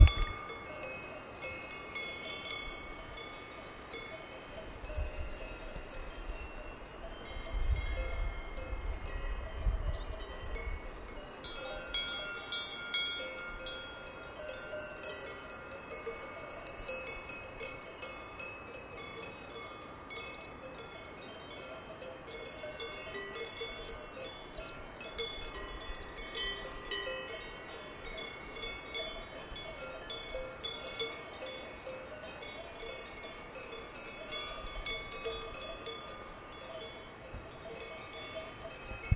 动物 " 阿尔卑斯山的奶牛 001
描述：一群牛在法国阿尔卑斯山高高地吃草。
标签： 牛铃 昆虫 法国 法语 板球 阿尔卑斯山
声道立体声